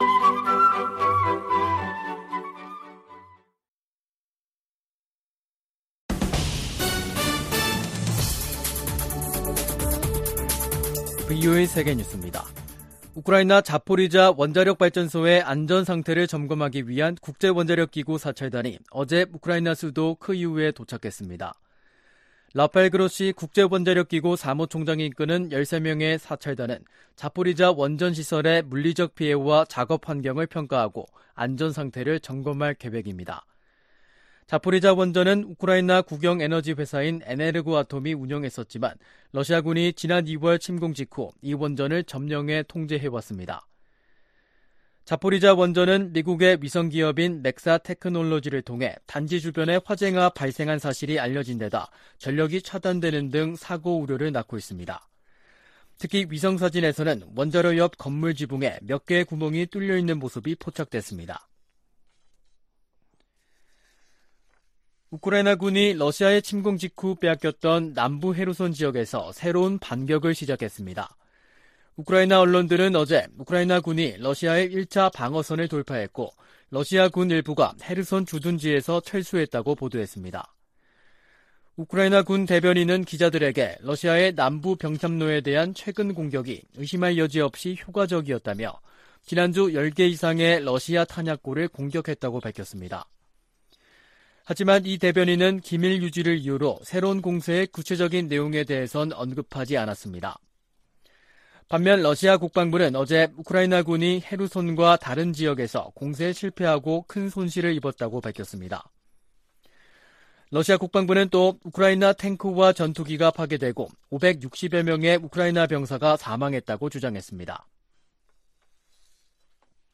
VOA 한국어 간판 뉴스 프로그램 '뉴스 투데이', 2022년 8월 30일 2부 방송입니다. 미 국무부는 북한과의 대화 필요성을 재확인하면서도 도발에 대응하고 제재를 이행하겠다는 의지를 강조했습니다. 권영세 한국 통일부 장관은 ‘담대한 구상’ 제안에 북한이 호응할 것을 거듭 촉구했습니다. 미 의회 내에서는 북한이 핵실험을 강행할 경우 북한과 거래하는 중국 은행에 ‘세컨더리 제재’를 가해야 한다는 목소리가 높습니다.